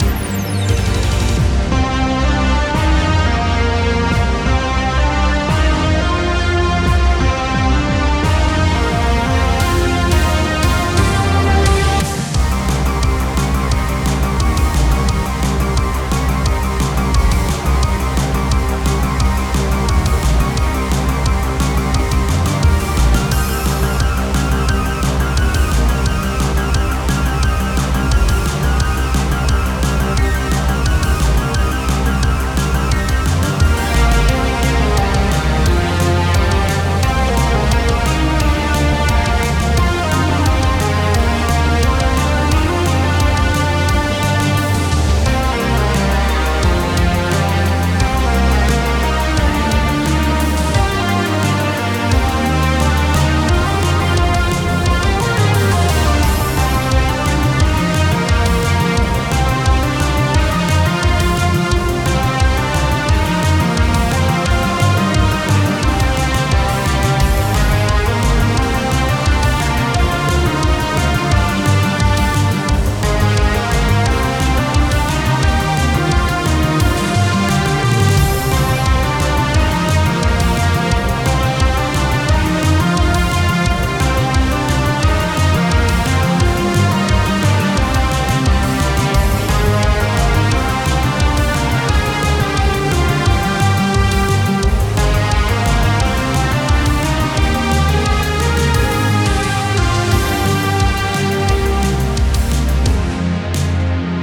Music for Boss battle theme.